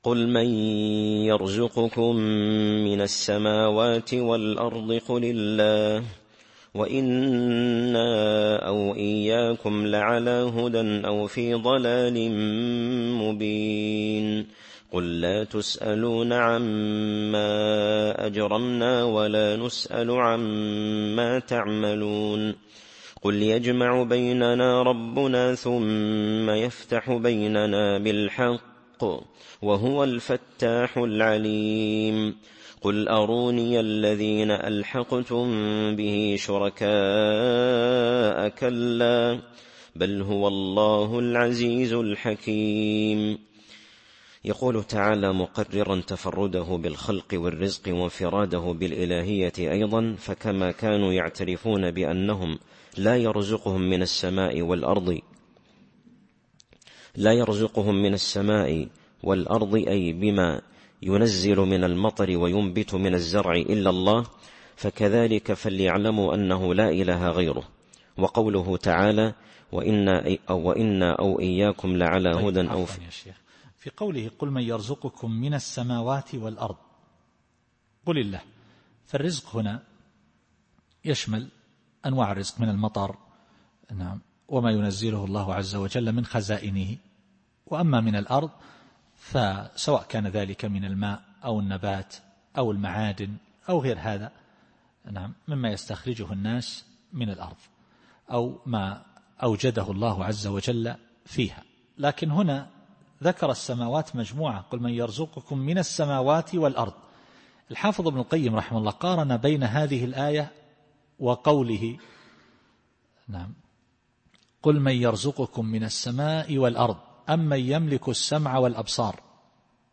التفسير الصوتي [سبأ / 24]